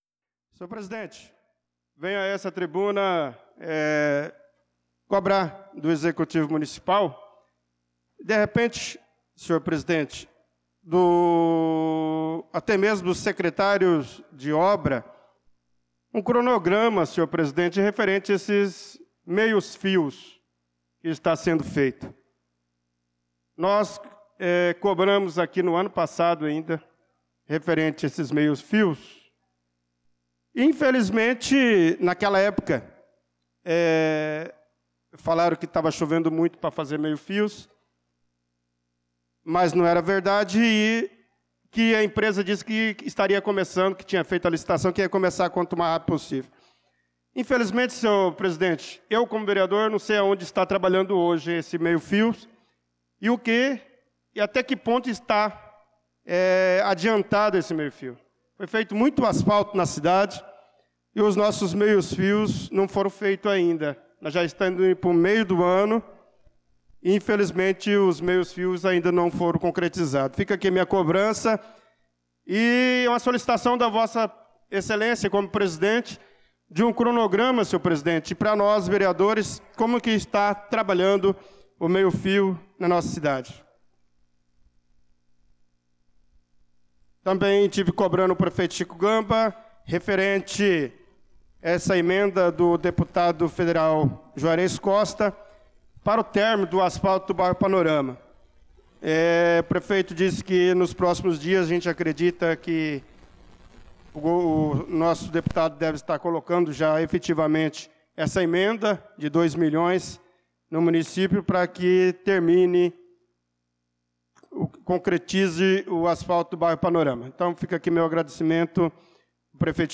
Pronunciamento do vereador Bernardo Patrício na Sessão Ordinária do dia 16/06/2025